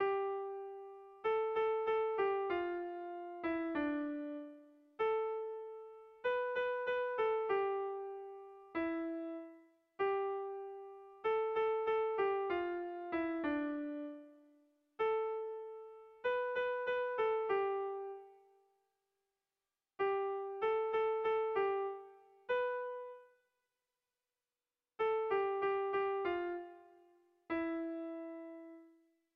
Kontakizunezkoa
Lauko txikia (hg) / Bi puntuko txikia (ip)
A1A2